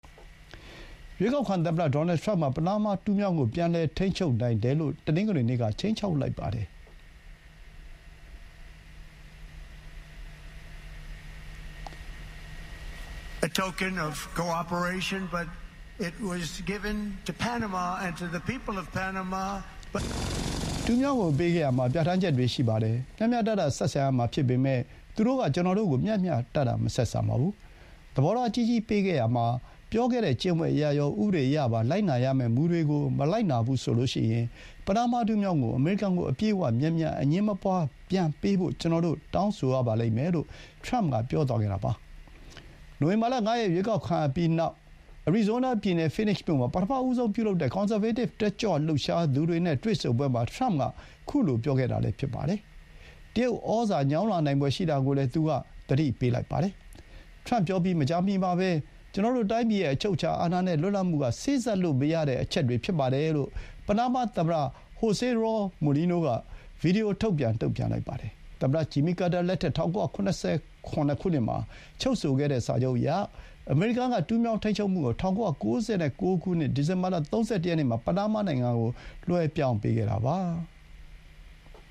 Phoenix မြို့ မှာ ပထမဆုံး ပြုလုပ်တဲ့ ကွန်ဆာဗေးတစ် တက်ကြွလှုပ်ရှားသူတွေ နဲ့ တွေ့ဆုံပွဲမှာ မိန့်ခွန်းပြောနေတဲ့ ရွေးကောက်ခံသမ္မတ ထရမ့် (ဒီဇင်ဘာ ၂၂၊ ၂၀၂၄)